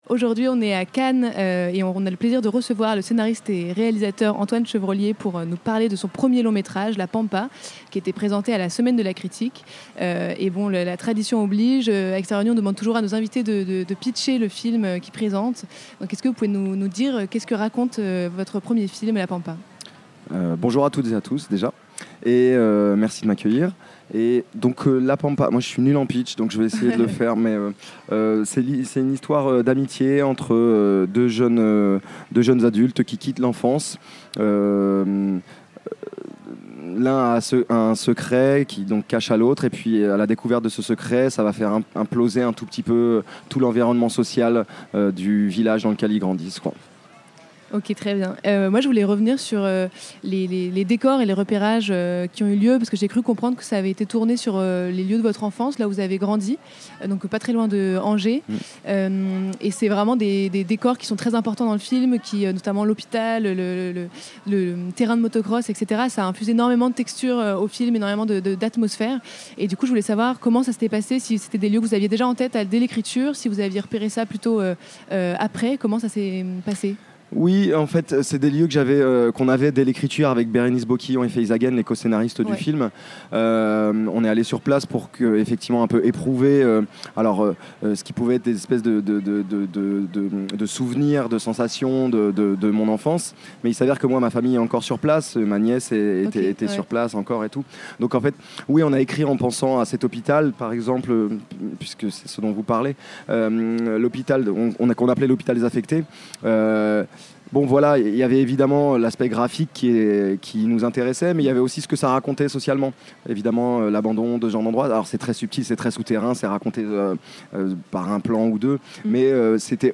Type Magazine Culture